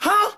Huh.wav